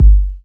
Tuned kick drum samples Free sound effects and audio clips
• Round Kickdrum Sample G Key 194.wav
Royality free kick drum tuned to the G note. Loudest frequency: 61Hz
round-kickdrum-sample-g-key-194-25t.wav